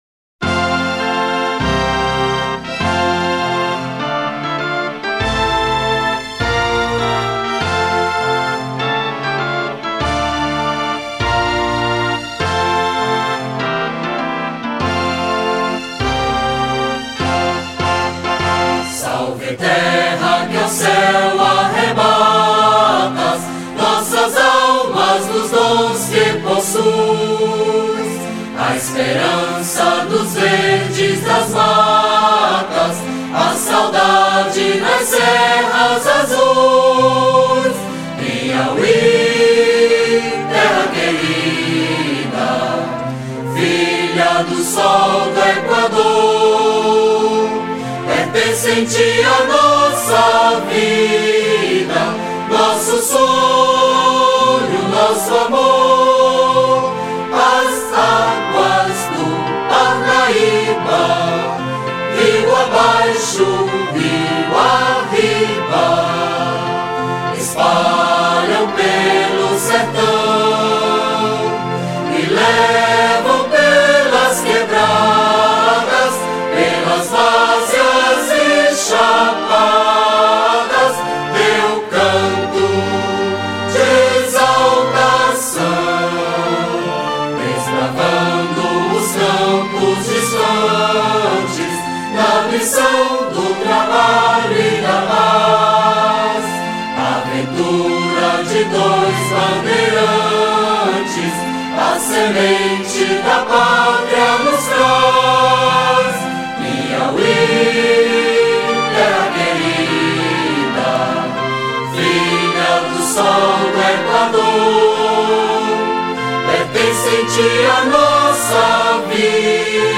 883   08:02:00   Faixa:     Hino